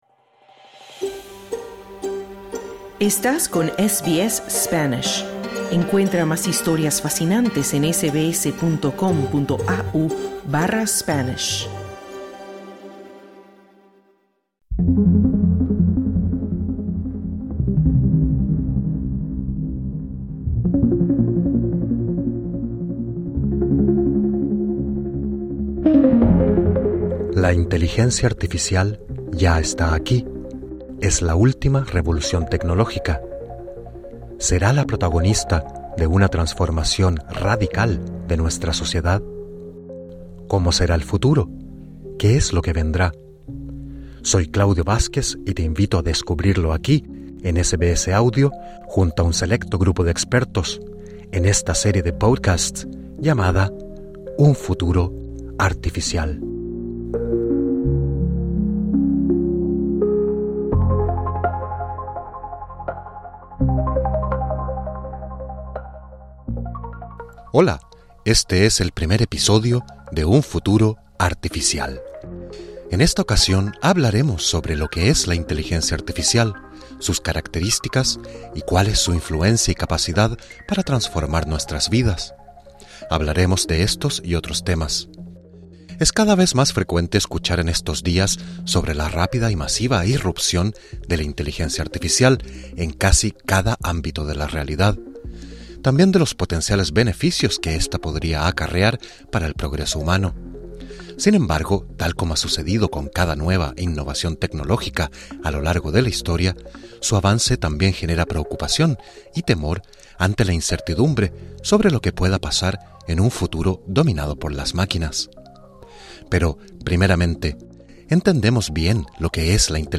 En la primera entrega de Un Futuro Artificial explicamos qué es la inteligencia artificial. Conversamos con el ingeniero en ciencia computacional